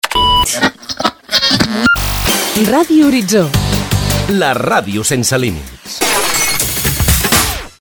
Dos indicatius de la ràdio "sense límits"